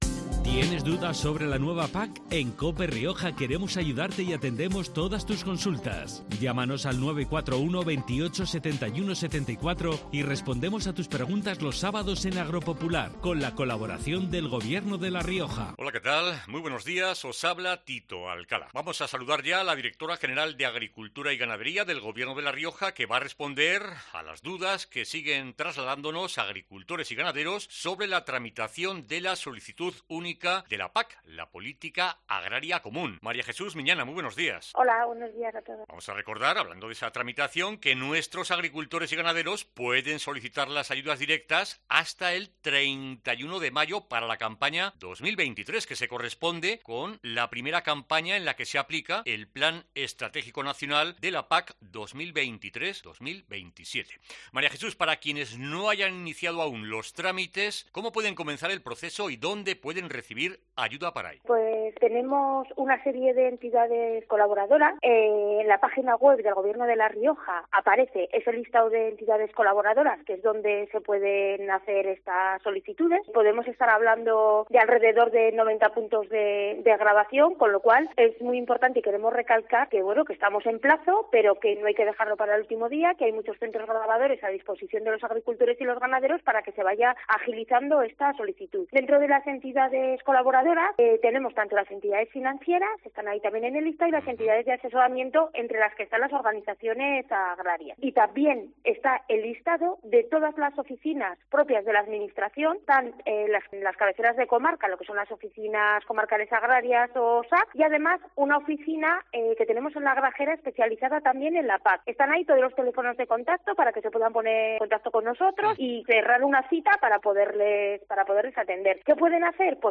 En el quinto programa, la directora general de Agricultura y Ganadería, María Jesús Miñana, ha explicado cómo pueden comenzar el proceso y dónde pueden recibir ayuda para ello quienes no hayan iniciado aún los trámites de solicitud de la campaña de la PAC 2023.